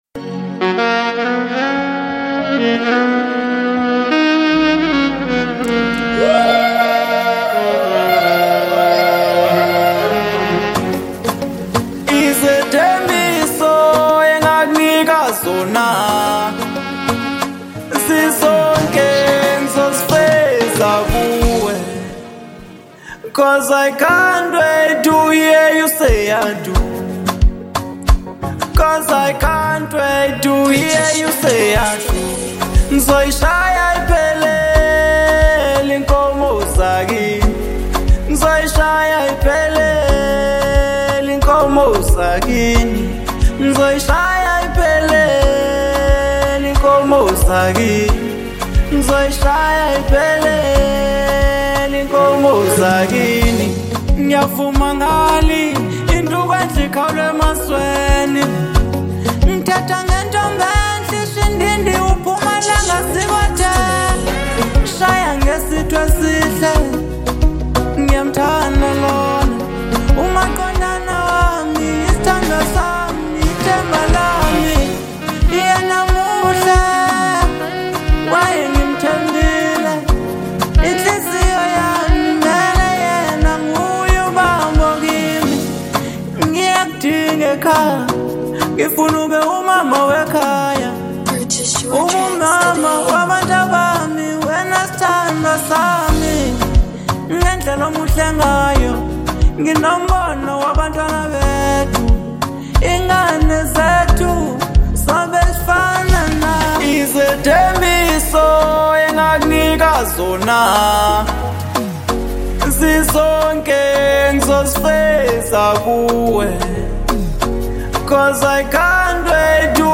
03:08 Genre : Afro Pop Size